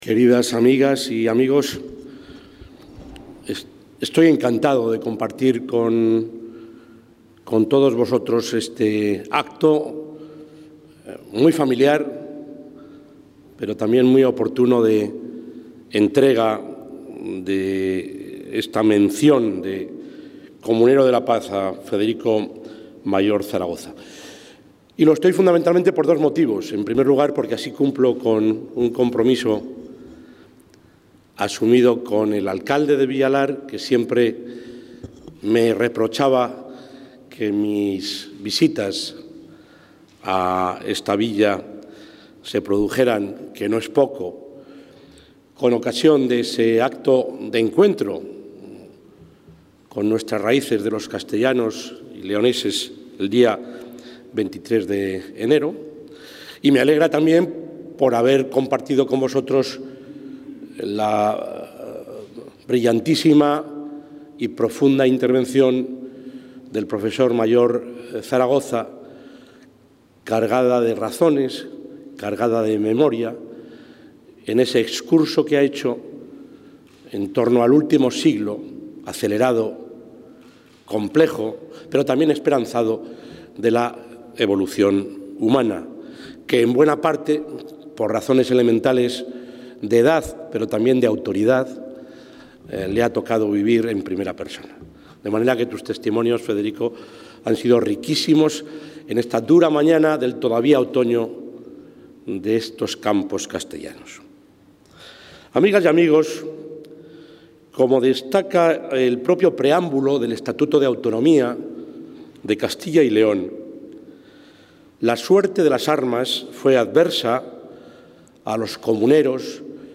Intervención del presidente de la Junta de Castilla y León.
El presidente de la Junta de Castilla y León, Juan Vicente Herrera, ha hecho entrega hoy de la Mención I Comunero de la Paz, que otorga el Ayuntamiento de Villalar de los Comuneros, a Federico Mayor Zaragoza.